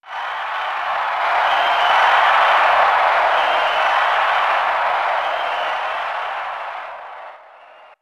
Super Smash Bros. game sound effects
Crowd Cheering.wav